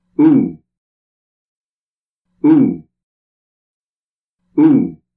ooo (long sound)